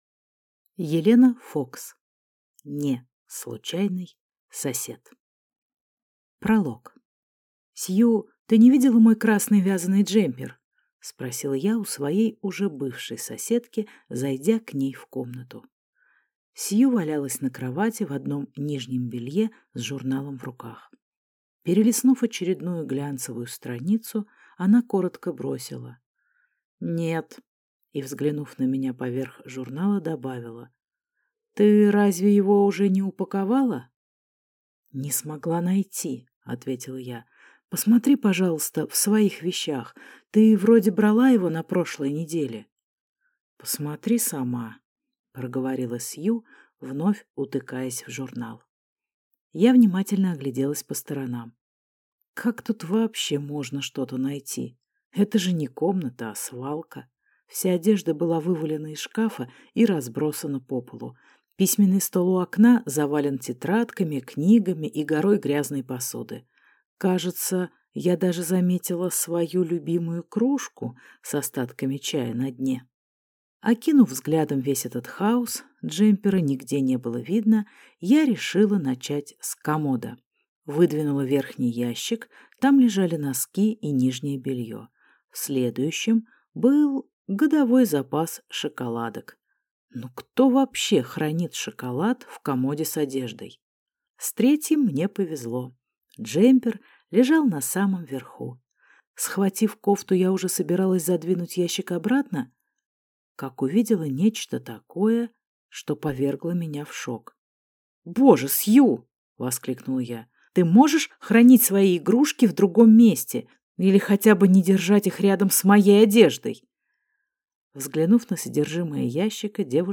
Аудиокнига (Не)случайный сосед | Библиотека аудиокниг